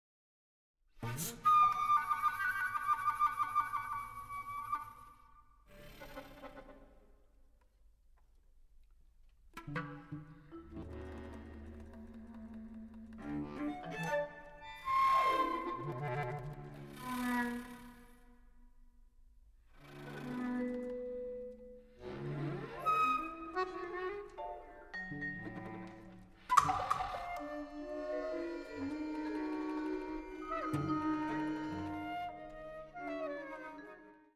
Quintett
Neue Musik
Modern/Avantgarde
Ensemblemusik